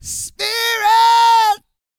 E-GOSPEL 121.wav